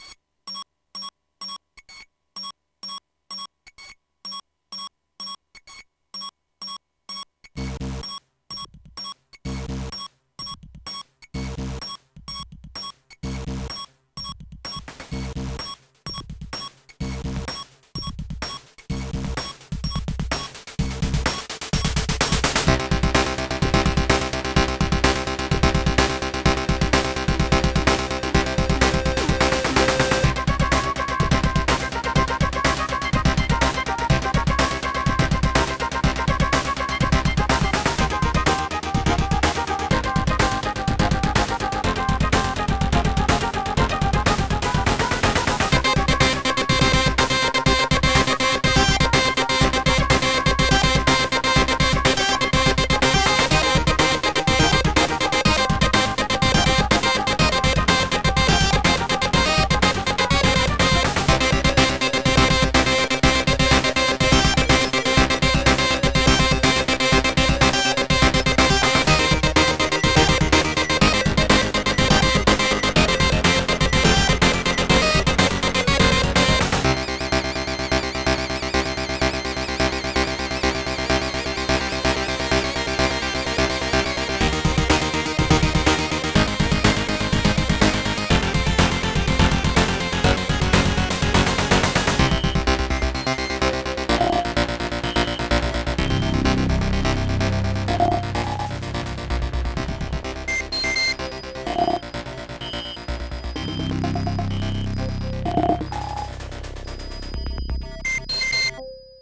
Intro music OPL3